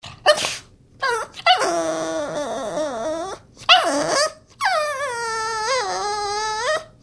cry3.mp3